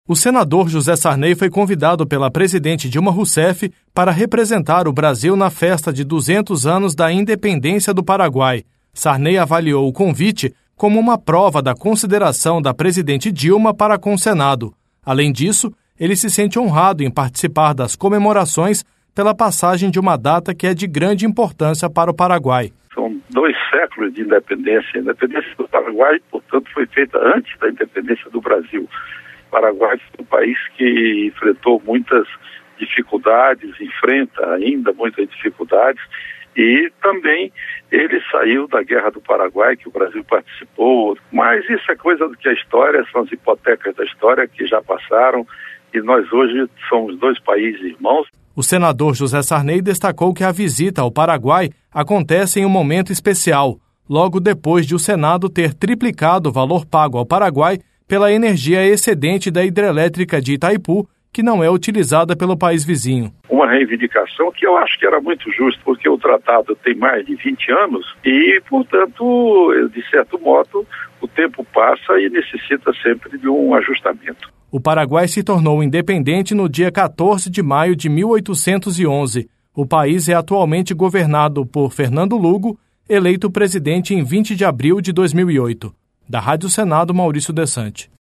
O PRESIDENTE DO SENADO, JOSÉ SARNEY, DO PMDB DO AMAPÁ, VAI REPRESENTAR O BRASIL NAS COMEMORAÇÕES DOS 200 ANOS DA INDEPENDÊNCIA DO PARAGUAI. ANTES DE EMBARCAR PARA O PAÍS VIZINHO, JOSÉ SARNEY FALOU COM EXCLUSIVIDADE AO PROGRAMA SENADO EM REVISTA, DA RÁDIO SENADO.